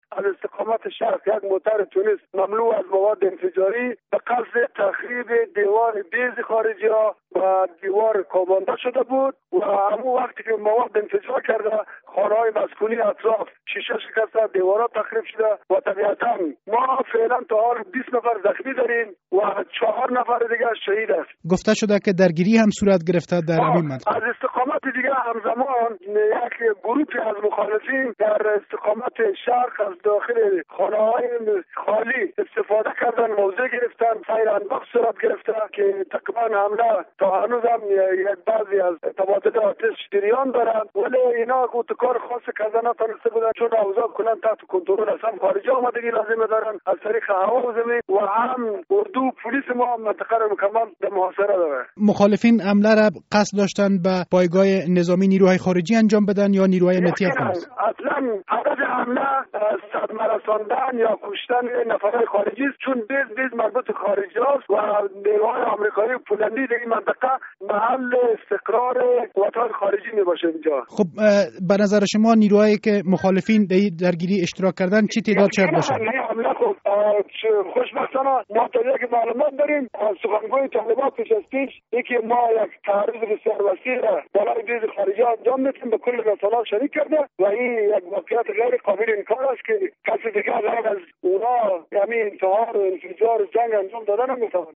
مصاحبه در مورد حمله گروهی بر یک مرکز نیروهای خارجی در غزنی